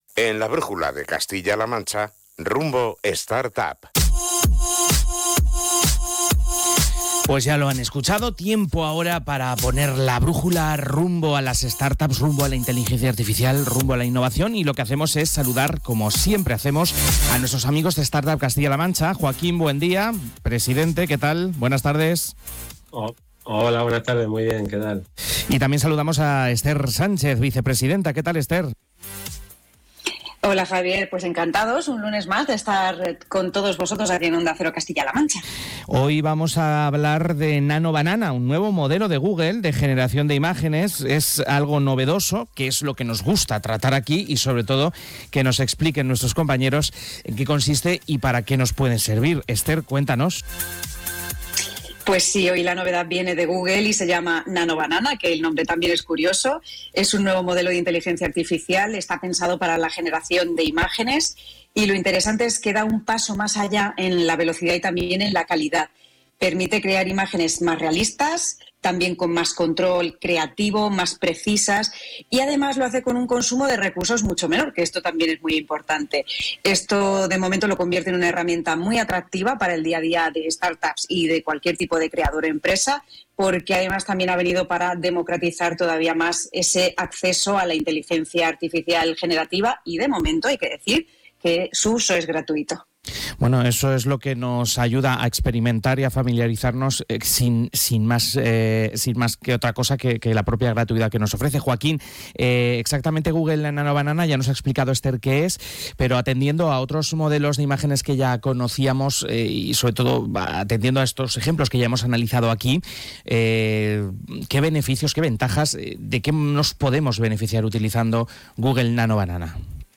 🙌 En el último episodio de «Rumbo Startup» dentro de «La Brújula de Castilla-La Mancha» nos sumergimos de lleno en el universo Nano Banana, el nuevo modelo de inteligencia artificial para imágenes de Google que lo está petando… ¡y con razón!